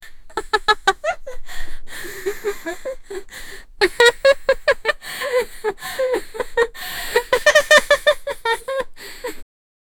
teenage-girl-laughing-mmromy42.wav